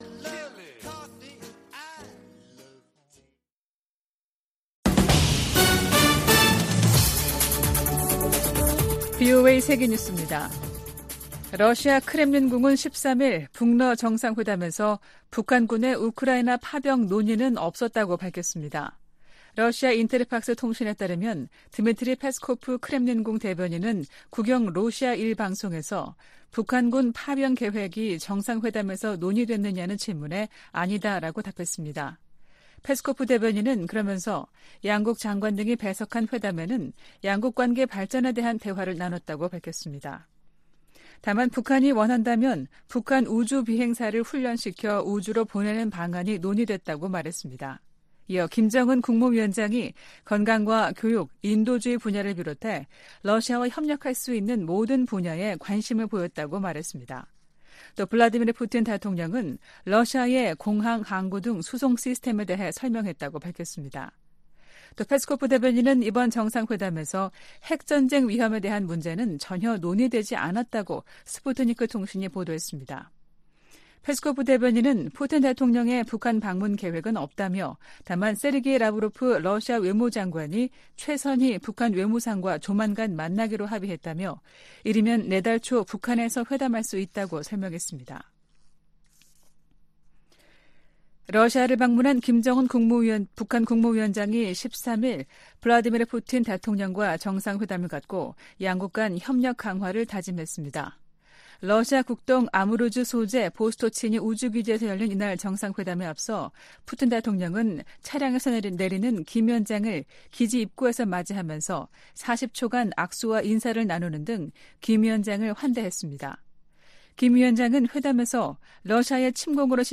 VOA 한국어 아침 뉴스 프로그램 '워싱턴 뉴스 광장' 2023년 9월 14일 방송입니다. 김정은 북한 국무위원장과 블라디미르 푸틴 러시아 대통령의 회담이 현지 시간 13일 오후 러시아 극동 우주기지에서 열렸습니다.